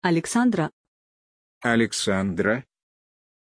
Aussprache von Alexandra
pronunciation-alexandra-ru.mp3